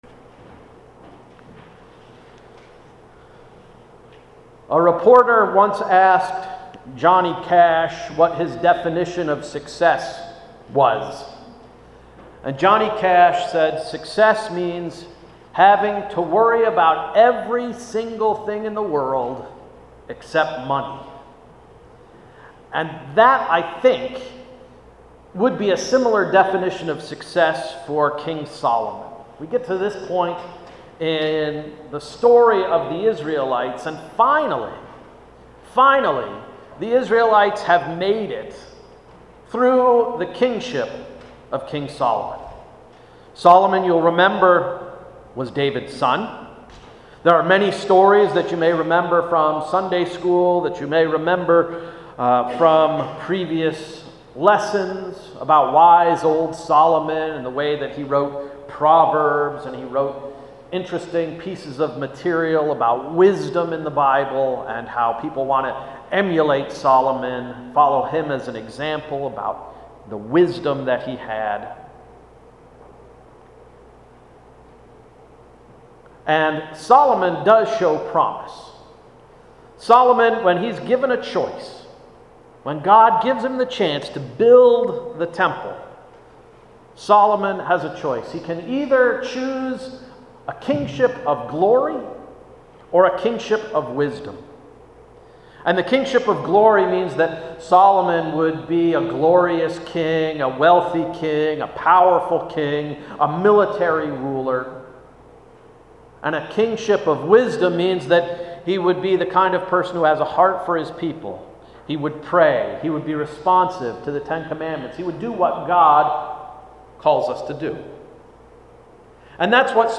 August 28, 2016 Sermon — “Heeeere’s Solomon!”